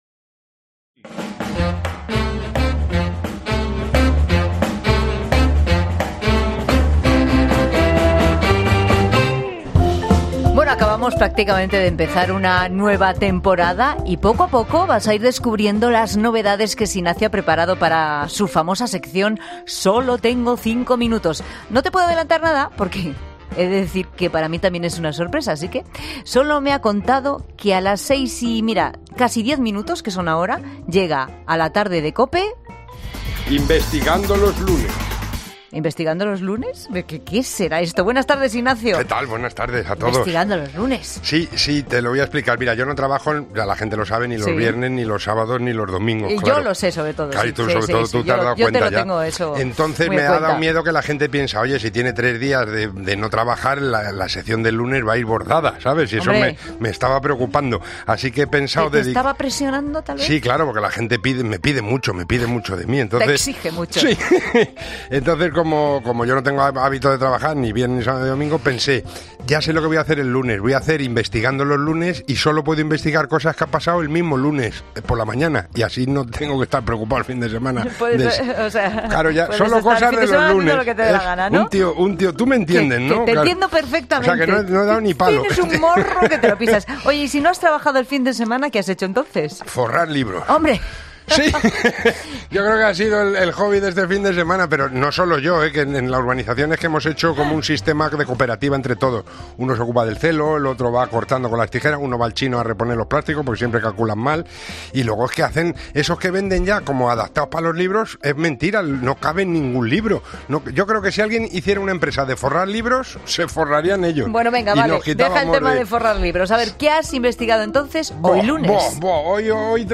Tendremos declaraciones de los compungidos padres tras dejar a los niños en el cole. Por supuesto, hablarán también los verdaderos protagonistas del día, los niños.
También hablamos con los profesores, ellos sí que se acuerdan de todo…. bueno casi.